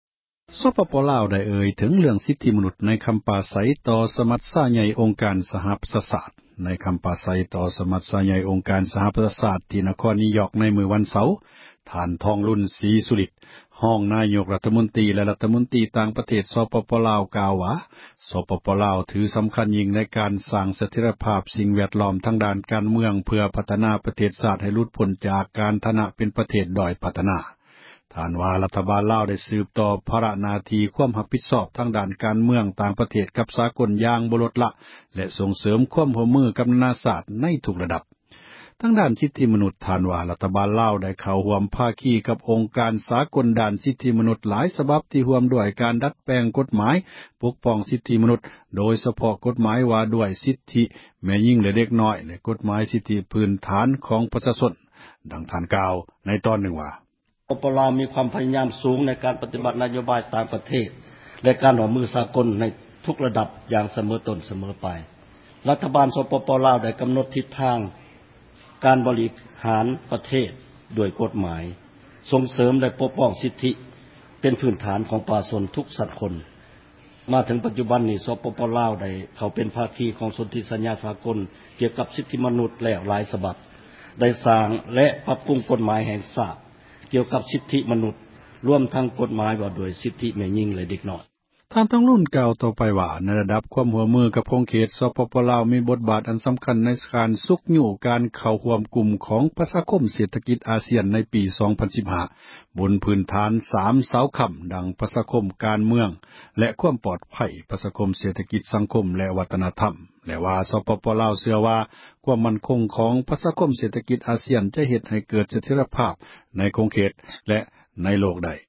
ໃນຄຳປາສັຍ ຕໍ່ສມັດໃຫຍ່ ອົງການ ສະຫະປະຊາຊາດ ທີ່ ນະຄອນ ນິວຢ໊ອກ ໃນມື້ວັນເສົາ ທ່ານທອງລຸນສີສຸລິດ ຮອງນາຍົກຣັຖມົນຕຣີ ແລະ ຣັຖມົນຕຣີການຕ່າງປະເທດລາວກ່າວວ່າ ສປປລາວ ຖືສຳຄັນ ຢ່າງຍິ່ງໃນການສ້າງ ສະເຖັຽຣະພາບ ສິ່ງແວດລ້ອມ ທາງດ້ານການ ເມືອງ ເພື່ອພັທນາ ປະເທດຊາດໃຫ້ຫລຸດພົ້ນ ຈາກຖານະການເປັນ ປະເທດດ້ອຍພັທນາ.